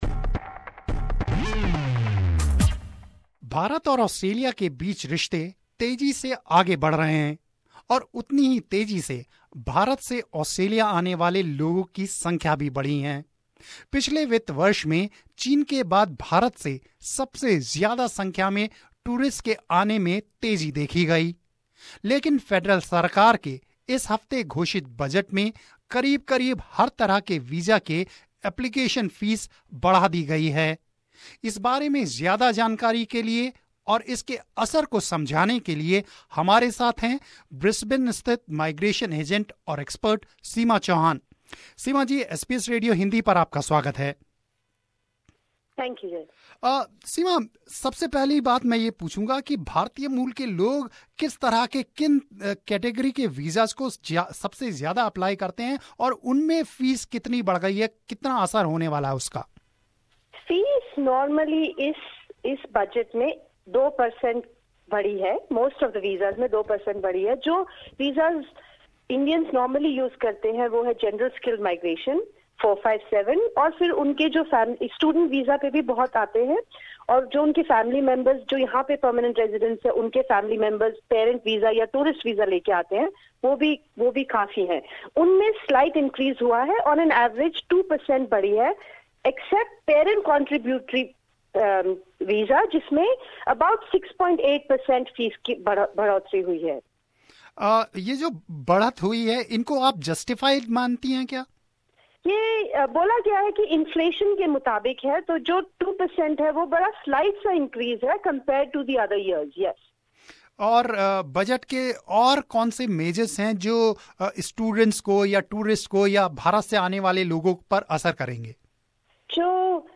had a conversation